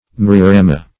Search Result for " myriorama" : The Collaborative International Dictionary of English v.0.48: Myriorama \Myr`i*o*ra"ma\, n. [Gr.
myriorama.mp3